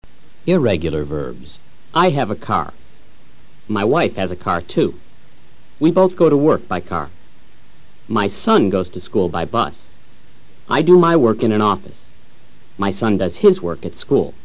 Escucha al profesor leyendo oraciones simples en tiempo PRESENTE SIMPLE.